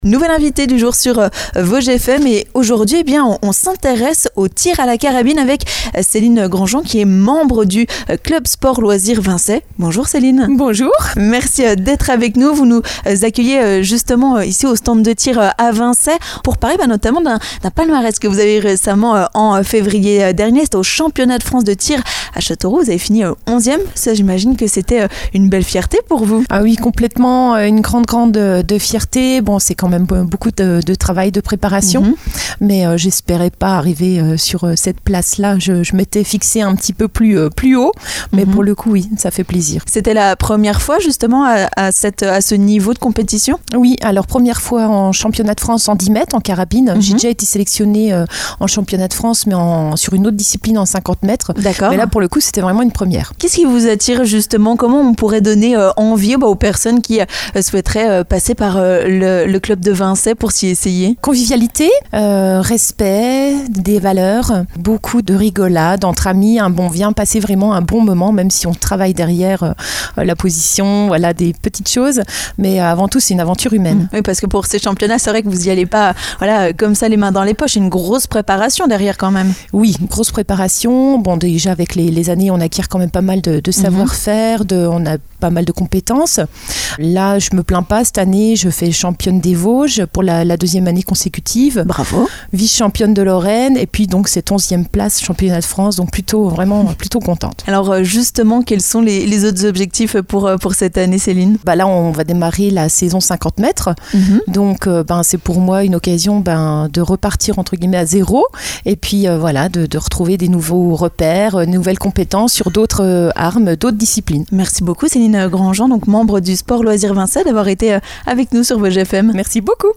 Nous partons à la découverte du tir à la carabine à Vincey, avec notre invitée du jour.